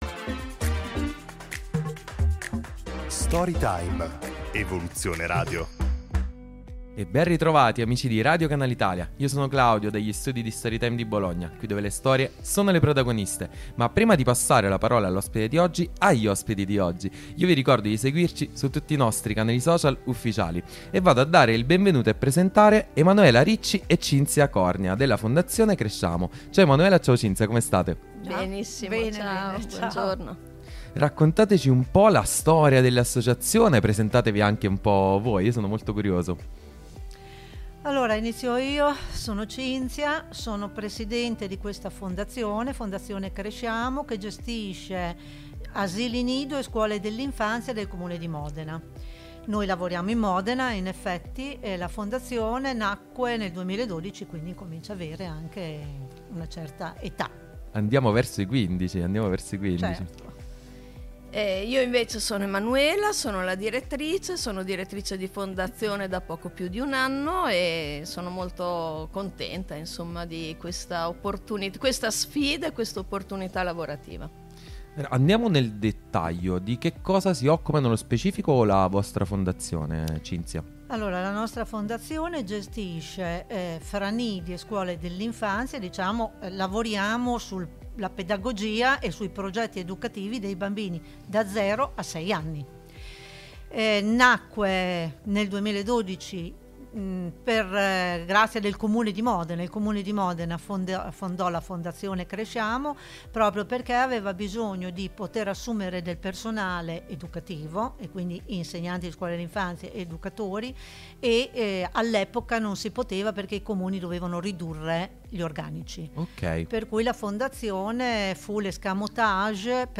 fondazione-cresciamo-intervista.mp3